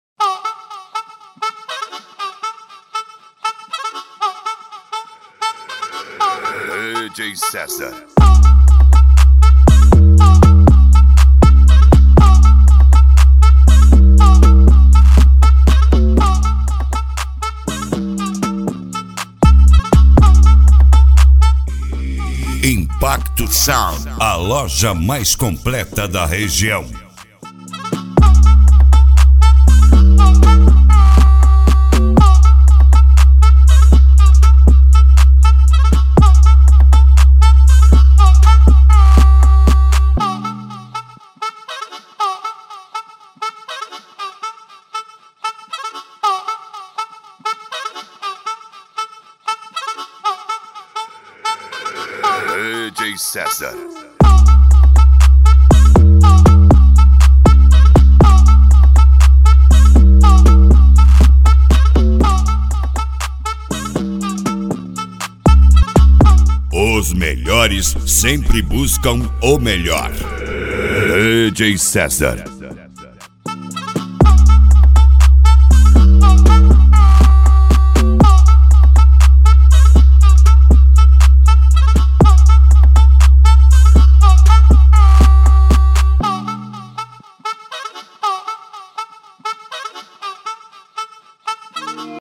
Funk Nejo
Mega Funk